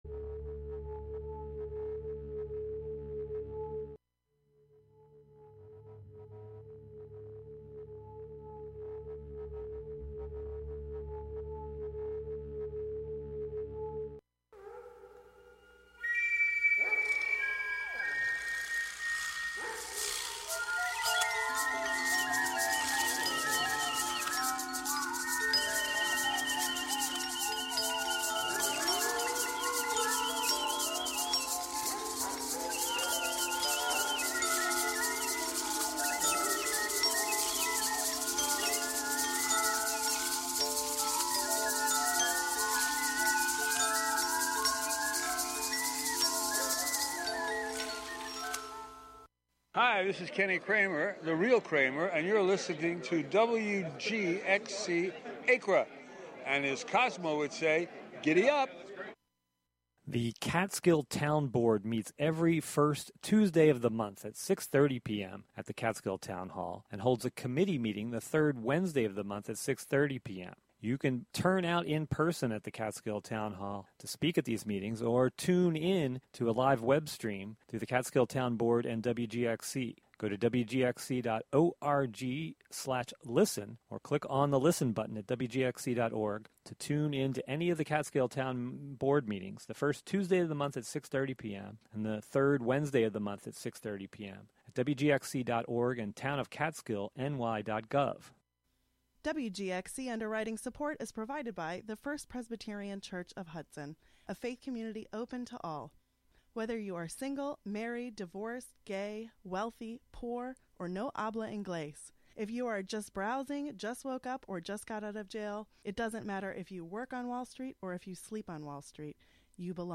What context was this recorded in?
The show is a place for a community conversation about issues, with music, and more.